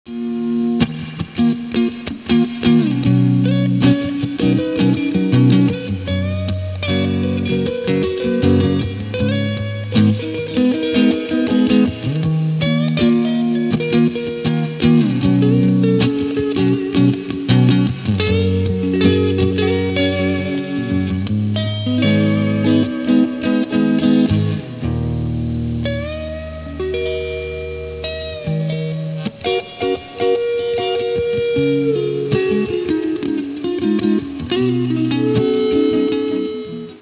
This was a bluesy thing I wrote a few years back.